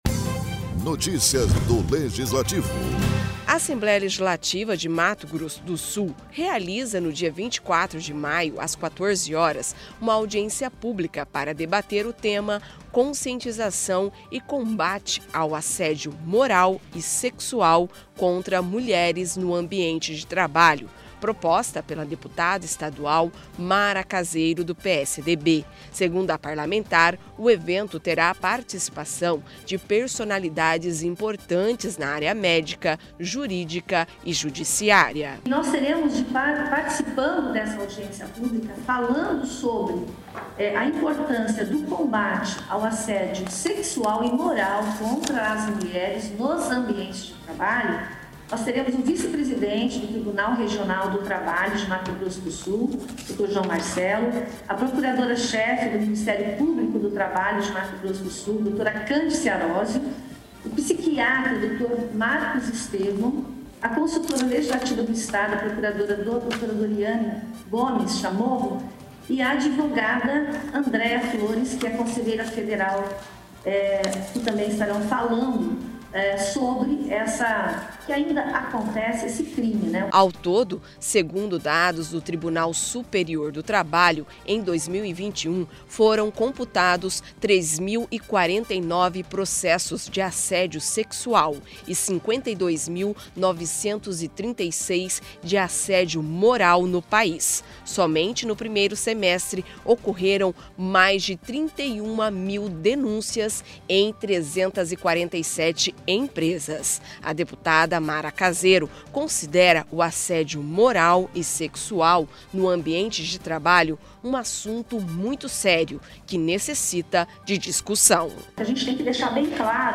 Produção e Locução